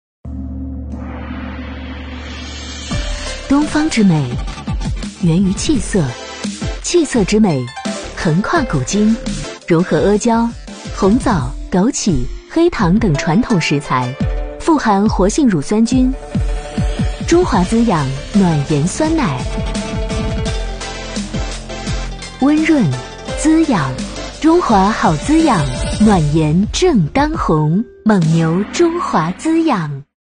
女66-广告 蒙牛中华滋养暖妍酸奶
女66大气专题 v66
女66-广告-蒙牛中华滋养暖妍酸奶.mp3